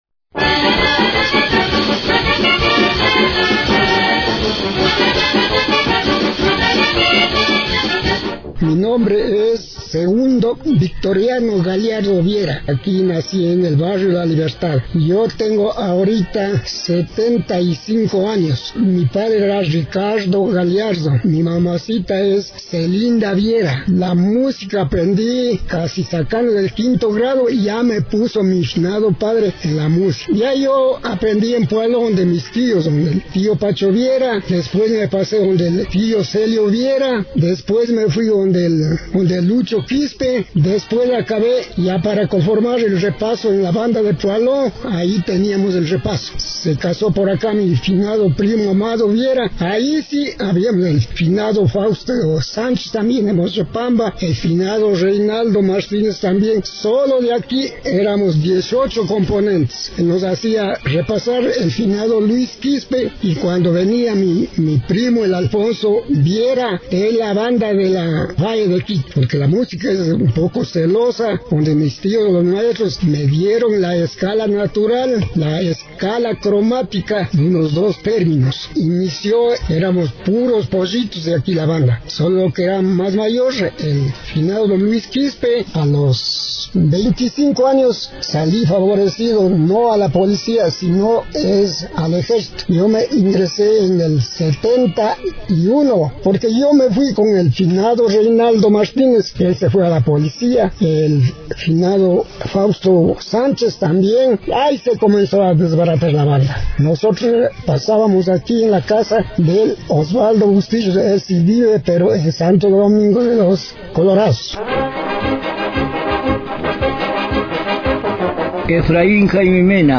Con la ilusión de hacer sonar los instrumentos como la trompeta, el clarinete, el bombo, el tambor, los platillos y convertirles en hermosas melodías, los jóvenes del barrio La Libertad se reunieron y conformaron la banda de músicos, casi todos aprendieron en la banda de músicos de Poalo, en esa época aprender a tocar un instrumento era un oficio que le entregaban sus padres.
albazos, pasillos, yaravíes, tonadas